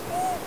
クーコールが聞けます。
屋久島3歳のクーコール